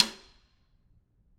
Percussion
Snare2-taps_v4_rr1_Sum.wav